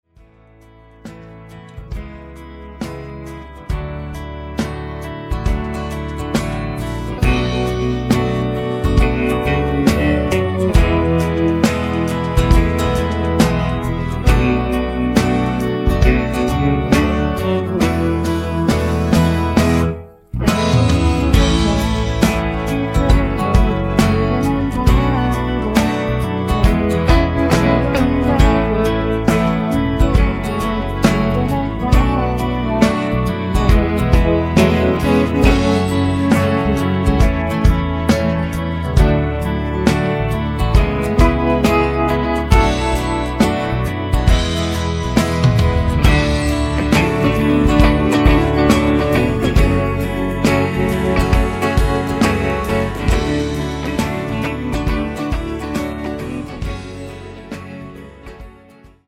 음정 원키 4:20
장르 가요 구분 Voice MR